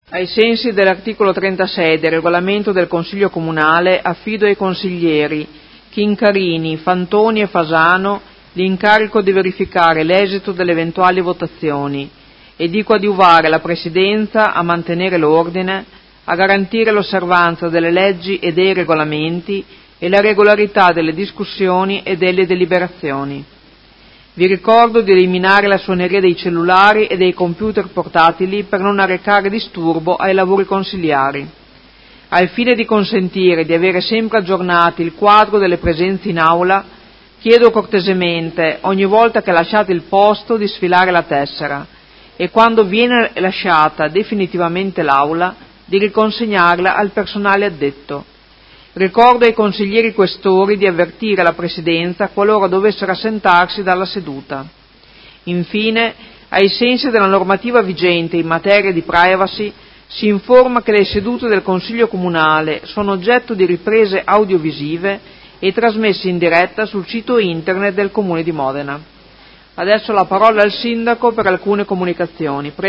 Presidentessa — Sito Audio Consiglio Comunale
Seduta del 17/01/2019 Apre i lavori del Consiglio.